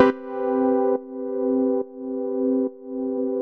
Index of /musicradar/sidechained-samples/140bpm
GnS_Pad-alesis1:2_140-C.wav